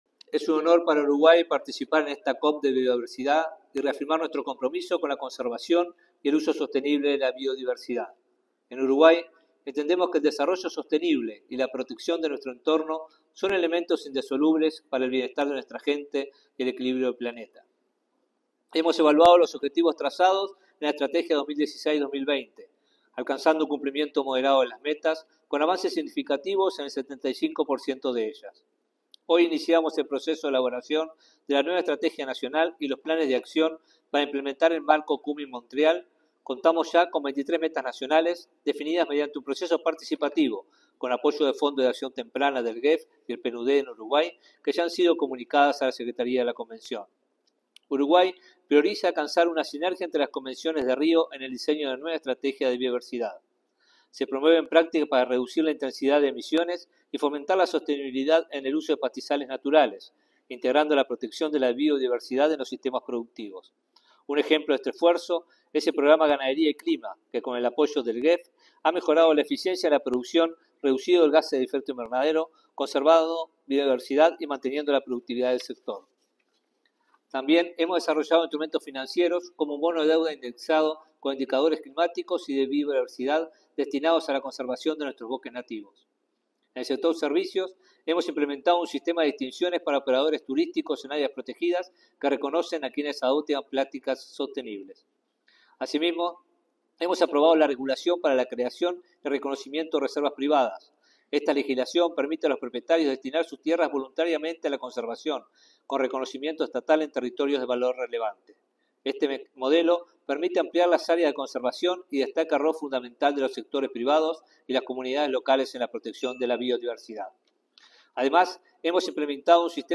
Palabras del ministro de Ambiente, Robert Bouvier